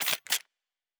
pgs/Assets/Audio/Sci-Fi Sounds/Weapons/Weapon 03 Reload 1.wav at 7452e70b8c5ad2f7daae623e1a952eb18c9caab4
Weapon 03 Reload 1.wav